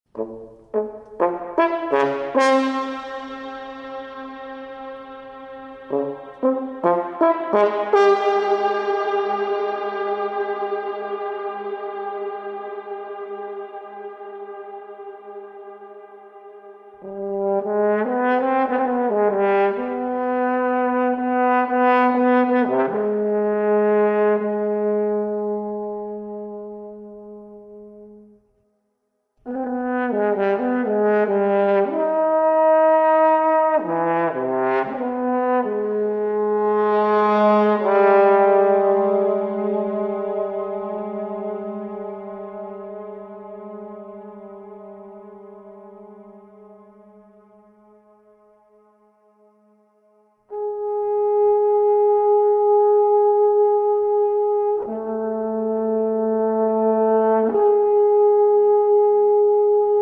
horn
For Horn and Electronics   9:08